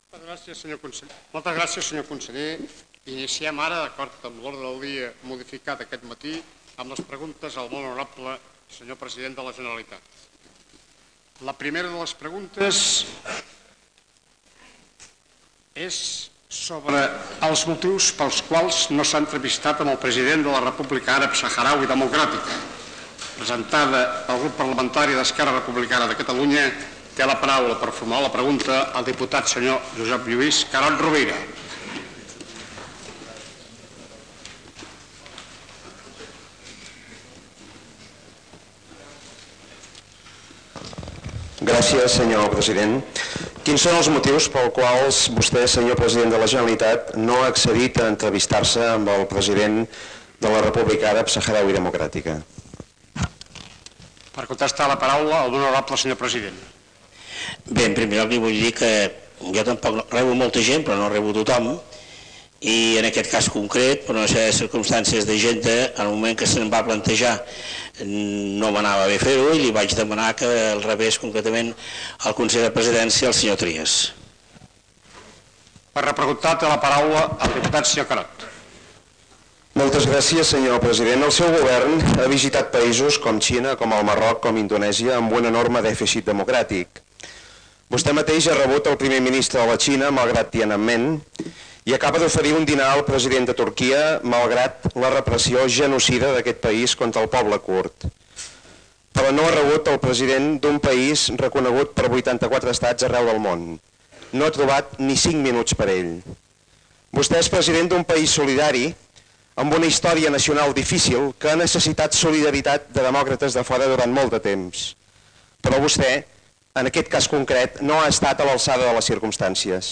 Sessió parlamentària